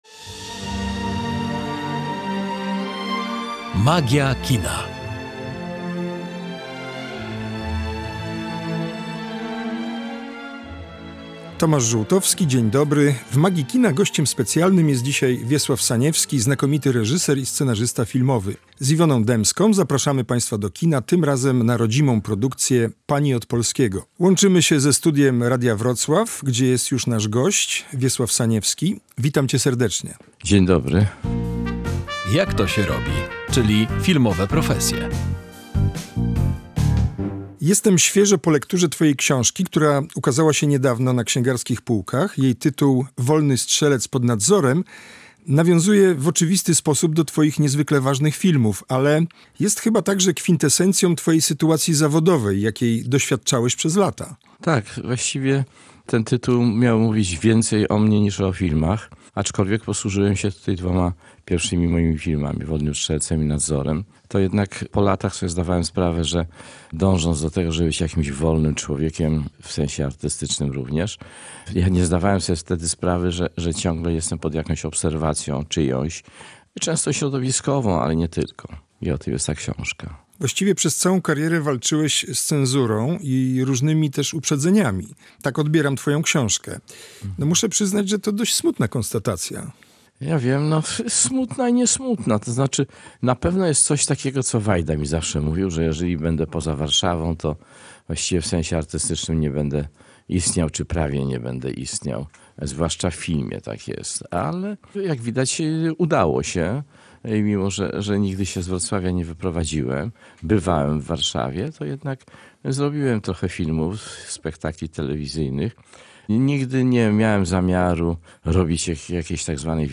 Spotkanie z Wiesławem Saniewskim, znakomitym reżyserem i scenarzystą filmowym, w „Magii Kina”